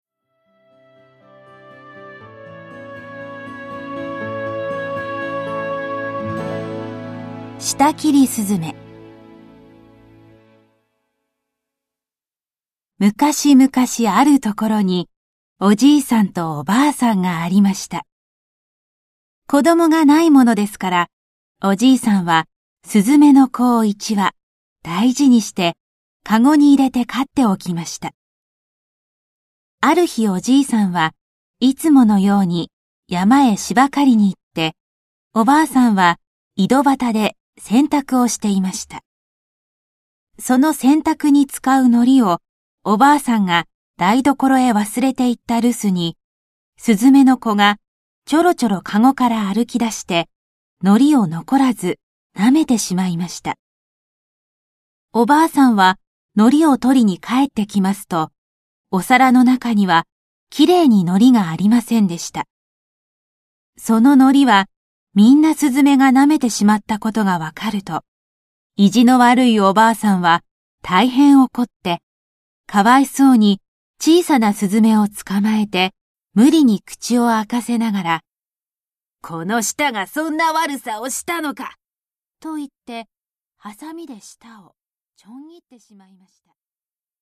[オーディオブック] 舌切りすずめ